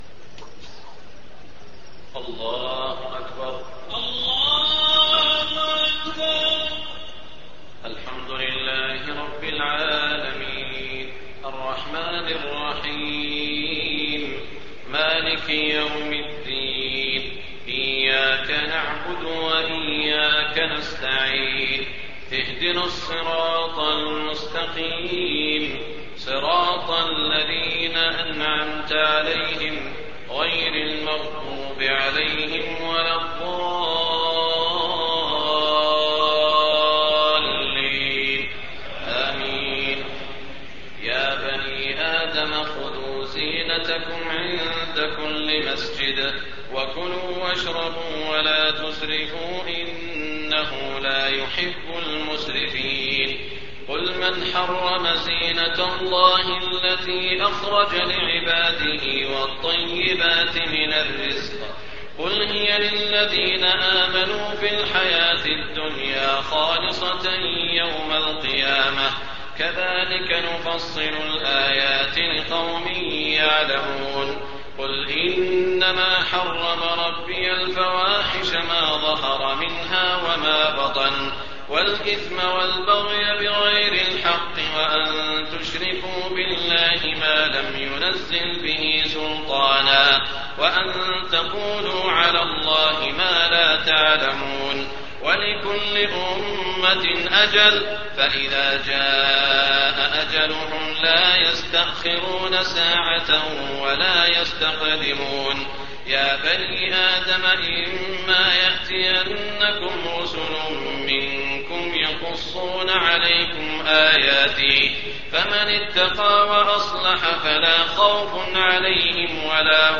تهجد ليلة 28 رمضان 1424هـ من سورة الأعراف (31-93) Tahajjud 28 st night Ramadan 1424H from Surah Al-A’raf > تراويح الحرم المكي عام 1424 🕋 > التراويح - تلاوات الحرمين